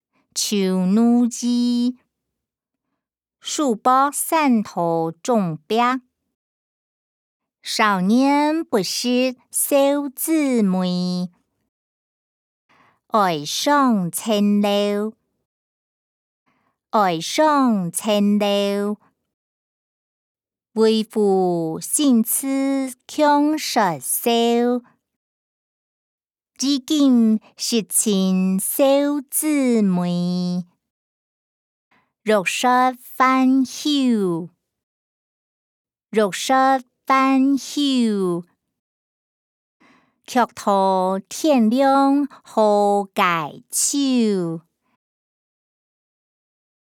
詞、曲-醜奴兒•書博山道中壁音檔(海陸腔)